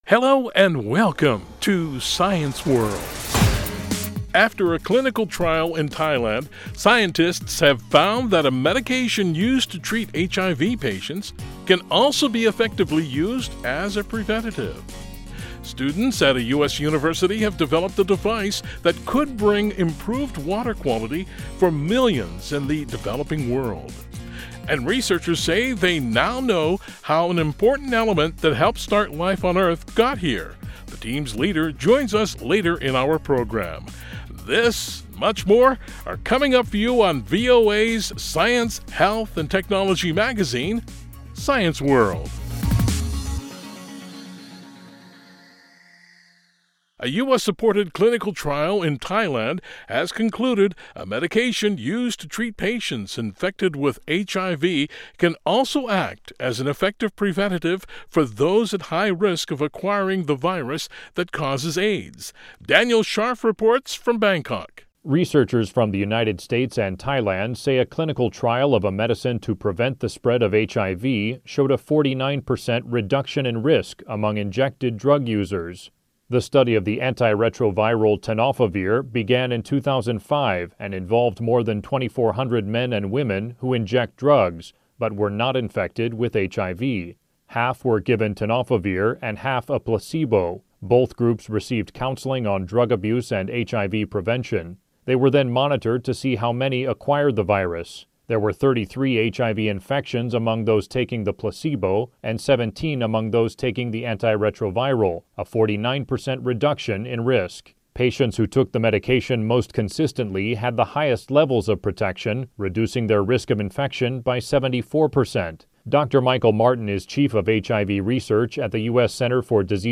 He joins us to talk about his team’s research and findings. Other stories we cover include: After a US sponsored clinical trial in Thailand, scientists have found that a medication used to treat HIV patients can also be effectively used as a preventative. Students at the University of Virginia have developed a device that could bring improved water quality for millions in the developing world.